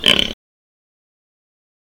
Snorting Pig
Another pig snort sound recorded at a farm.